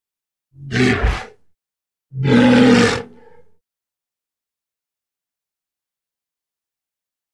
В подборке представлены характерные аудиозаписи: мощный рев и коммуникационные сигналы этих удивительных животных.
Рычание моржа